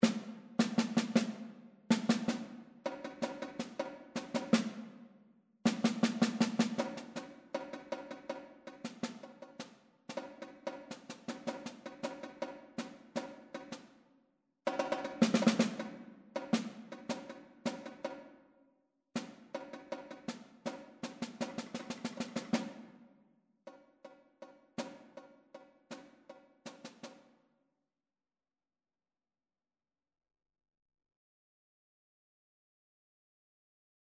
The given task for the second project is to compose four short pieces for two unpitched percussion instruments.
1) Snare Drum & Hand Drum
I generally would describe this piece as an argument between the two instruments, that ends with compromise.
Alternately, they are getting louder and quieter until in bar 7 both of them reach their loudest point. From then until the end the instruments are only getting quieter until the end.